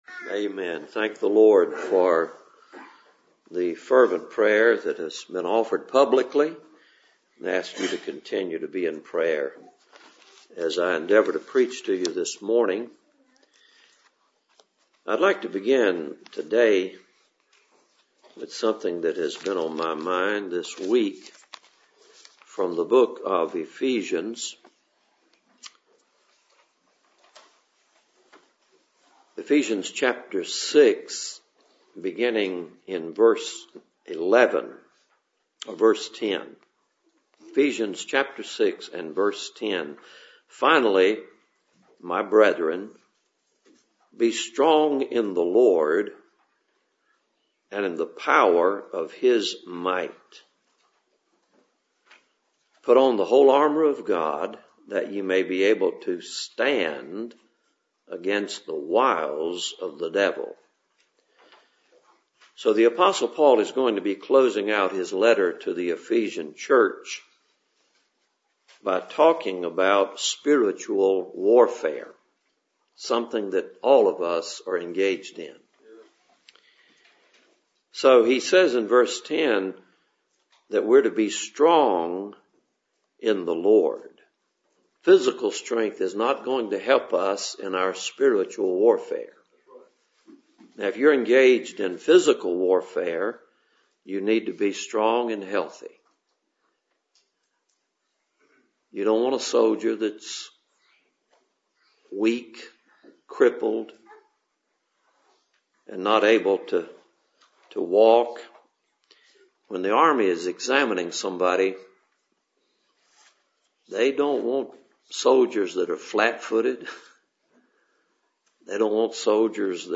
Passage: Ephesians 6:10-11 Service Type: Cool Springs PBC Sunday Morning